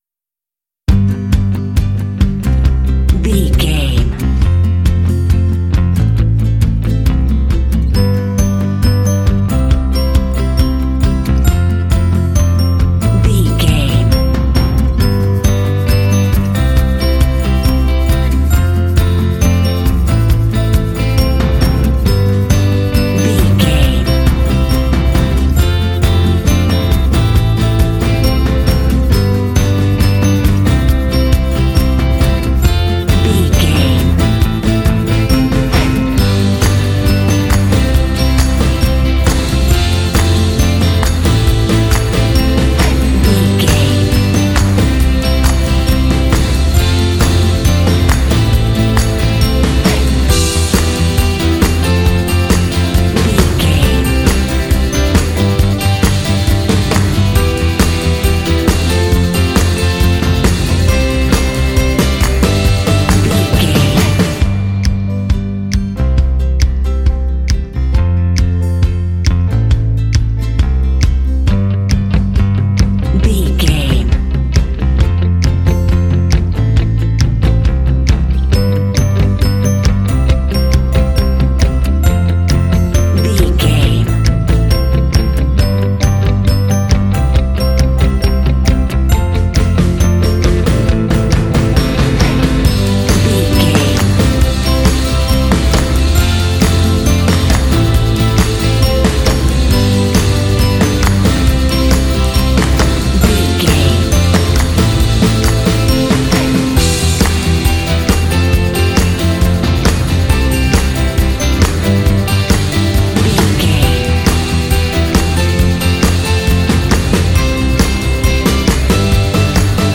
Fun and cheerful indie track with bells and “hey” shots.
Uplifting
Ionian/Major
playful
acoustic guitar
electric guitar
bass guitar
percussion
drums
piano
indie
alternative rock
contemporary underscore